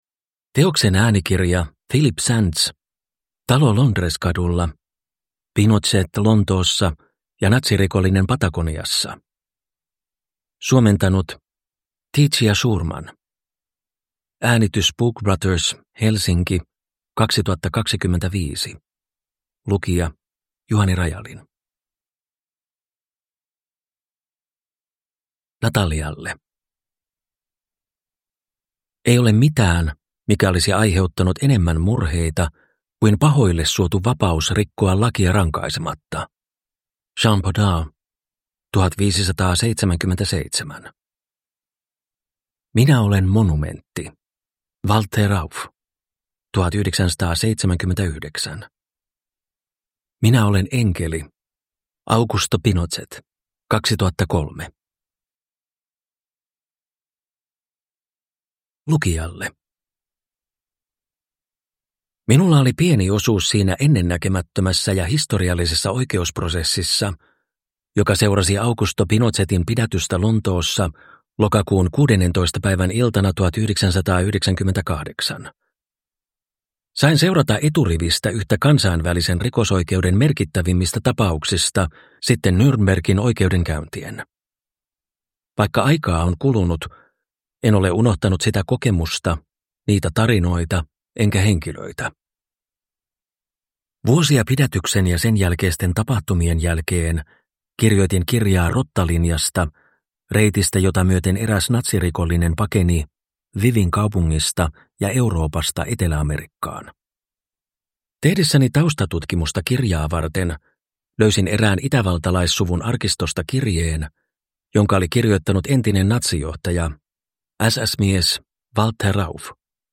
Talo Londres-kadulla – Ljudbok